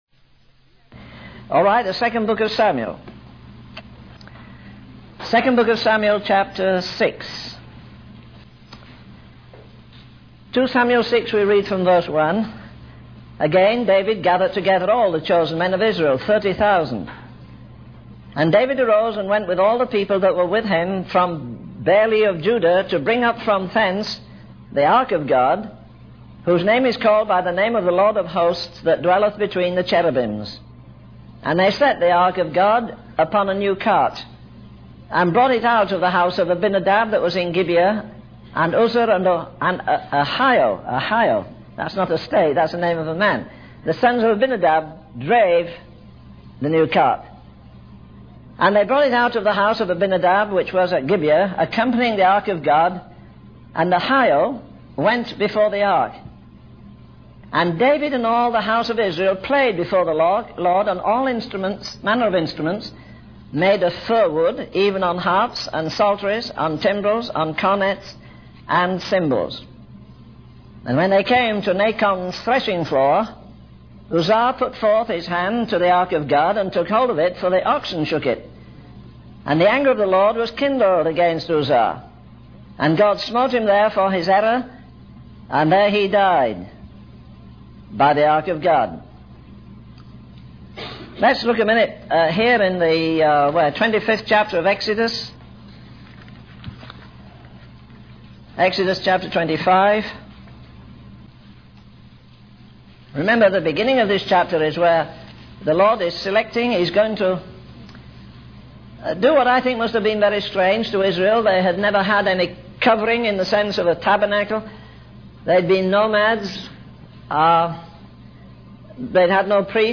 In this sermon, the preacher expresses concern about the mechanical and superficial nature of preaching the gospel. He criticizes the reliance on books and conferences, emphasizing the need for a genuine demonstration of God's power.